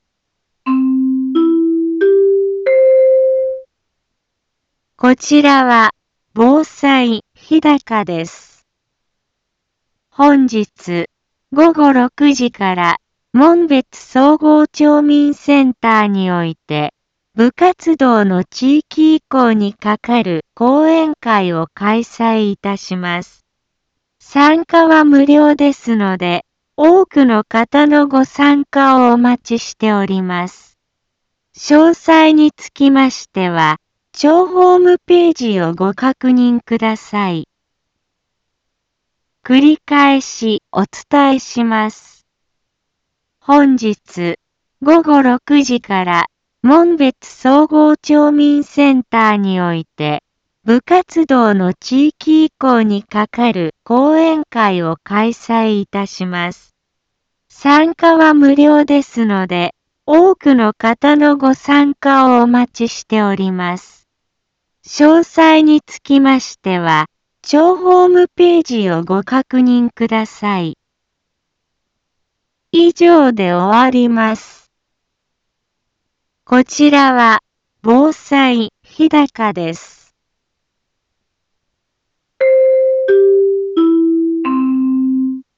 一般放送情報
BO-SAI navi Back Home 一般放送情報 音声放送 再生 一般放送情報 登録日時：2024-03-04 10:03:02 タイトル：学校部活動の地域移行に係る講演会の開催 インフォメーション： 本日、午後6時から、門別総合町民センターにおいて、部活動の地域移行に係る講演会を開催いたします。